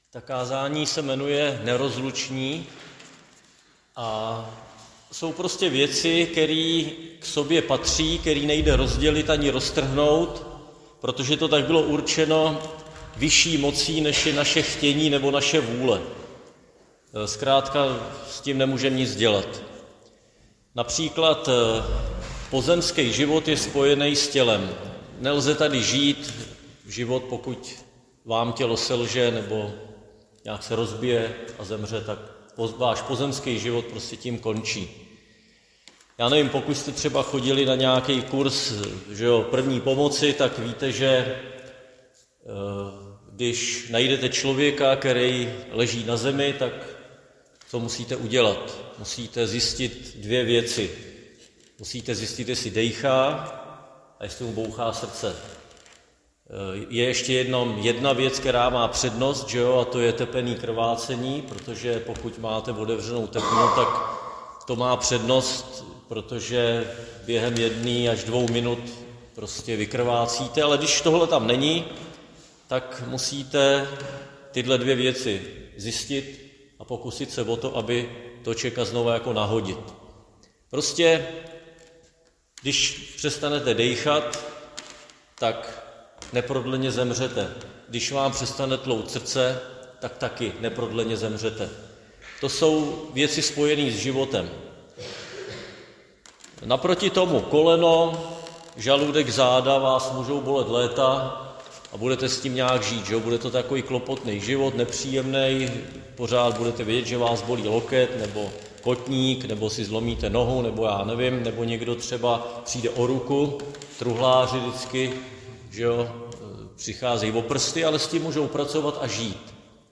Křesťanské společenství Jičín - Kázání 2.5.2024